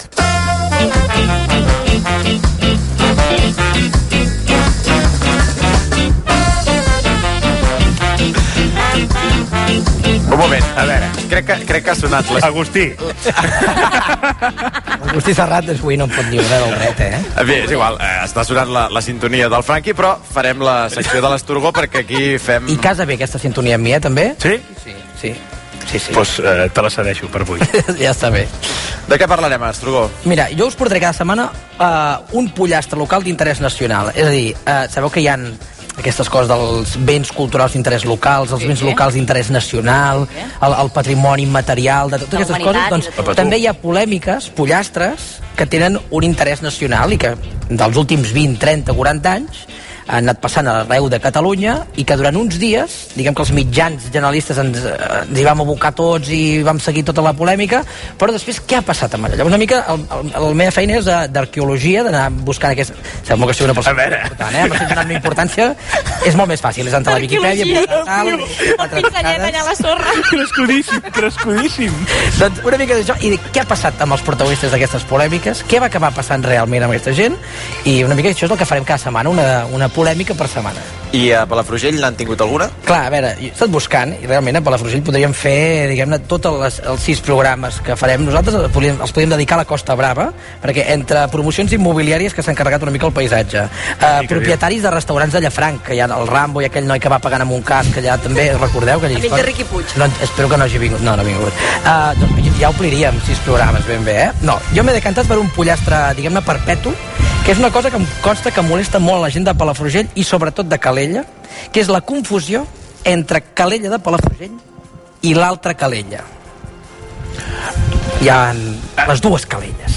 El programa d’ahir de “El Mon a RAC1” de l’emissora del Grup Godó es feia des del Museu Can Mario de Palafrugell (Baix Empordà) i es feien aquesta pregunta. Parlaven, en to d’humor i irònic, de la confusió entre Calella de Palafrugell i Calella -de la costa, diuen mal dit, tot i que el locutor reconeix que no ens agrada-.
El locutor fa humor comparant la música que s’oferia a les discoteques calellenques amb el ritme de les havaneres de Calella de Palafrugell.